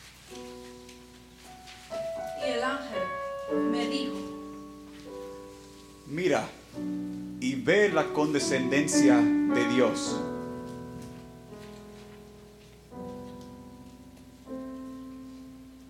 Soprano, Tenor, and Piano